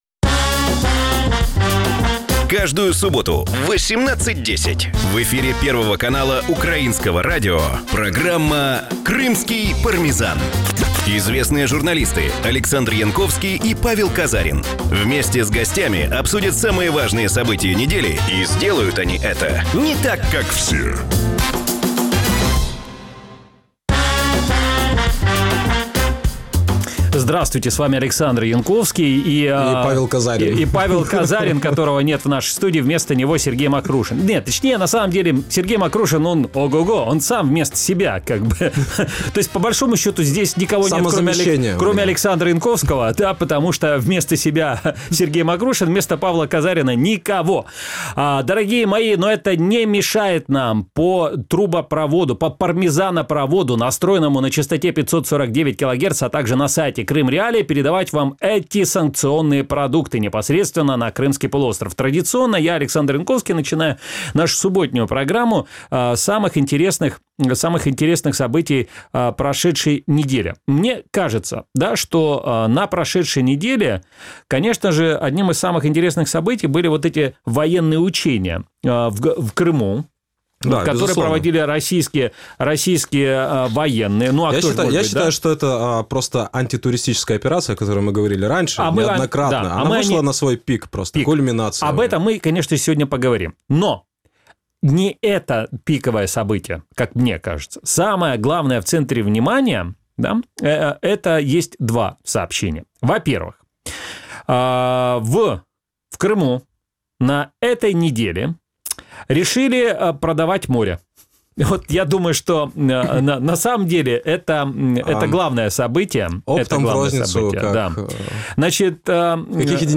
За кого проголосують кримчани на виборах, чому США вводять нові санкції проти Росії і як вилетіти з Криму за 48 годин – відповіді на ці питання в нашій програмі. Програма звучить в ефірі Радіо Крим.Реалії. Час ефіру: 18:10 – 18:40.